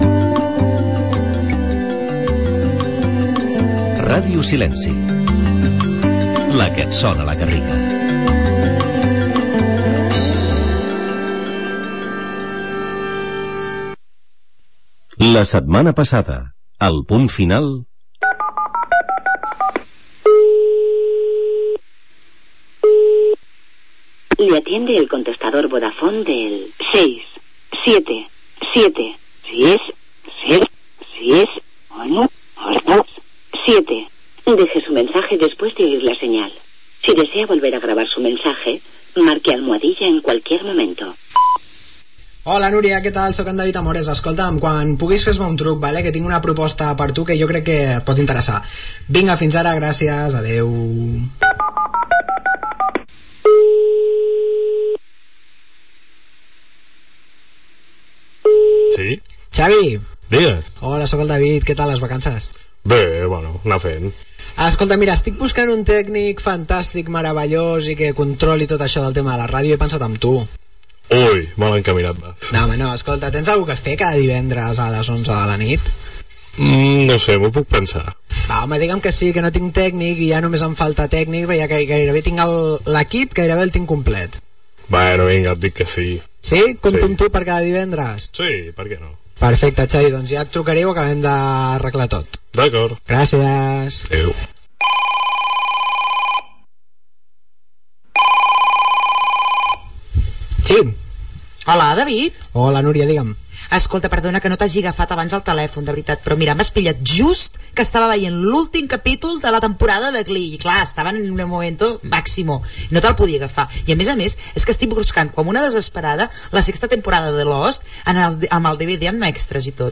Indicatiu de la ràdio
tema musical Gènere radiofònic Entreteniment